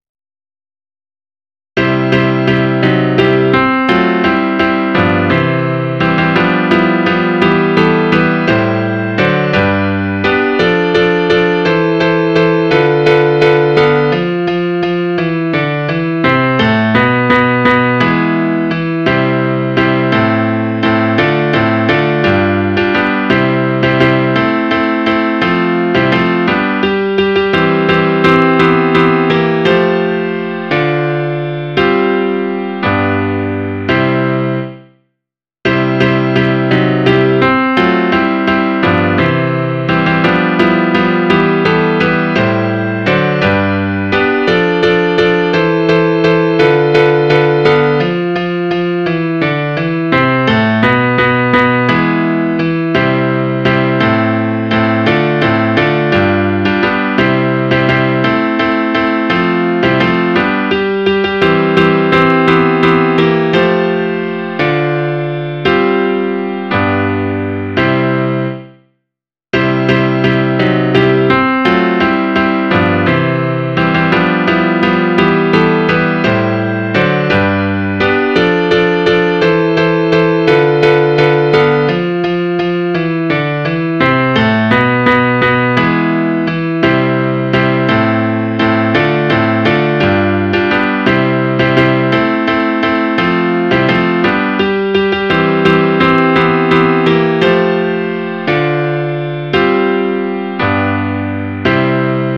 Midi File, Lyrics and Information to Three Fishers